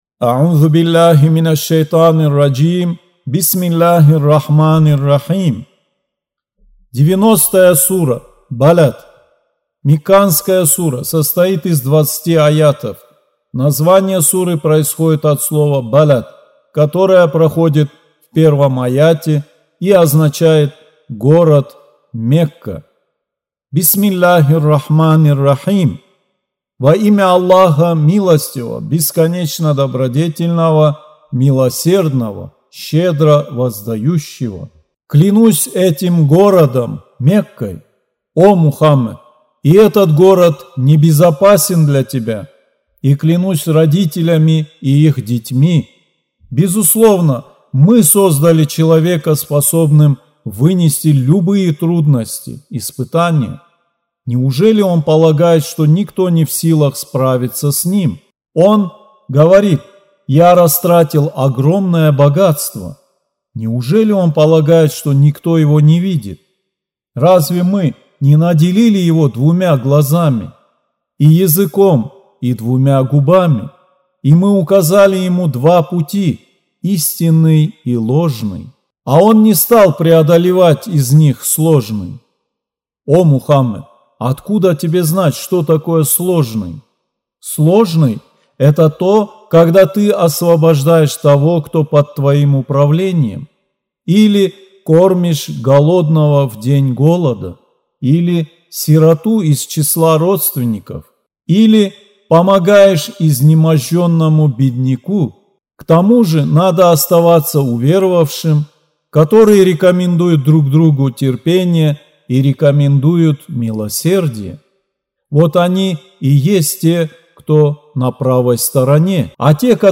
Аудио Коран 90.